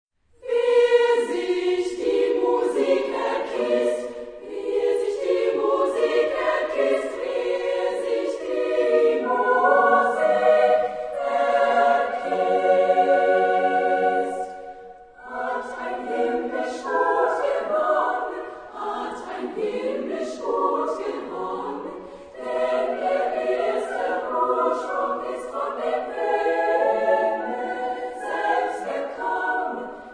Genre-Style-Form: Motet ; Secular
Type of Choir: SSSAAA  (6 women voices )
Tonality: B flat major